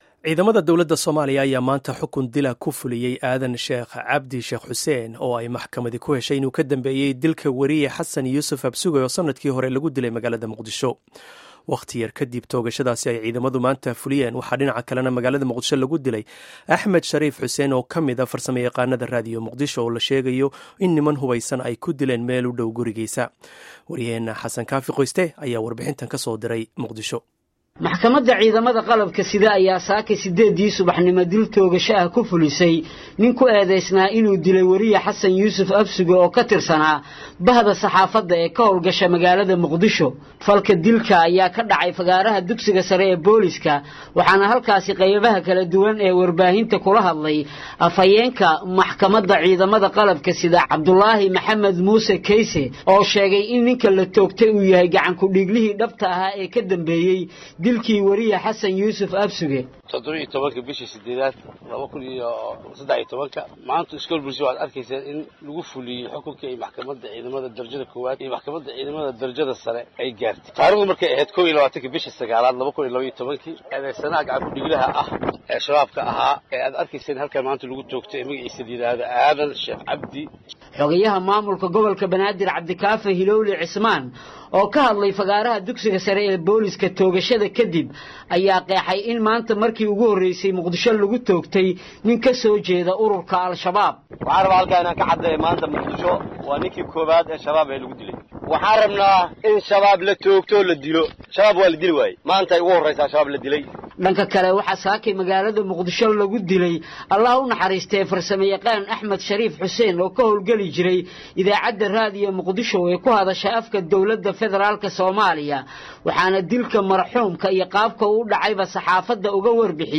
Dhageyso Warbixinta iyo Wareysiga